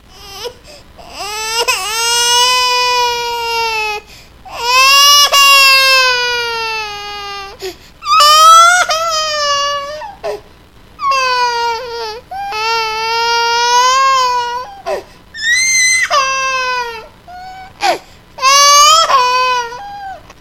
Baby Cry Long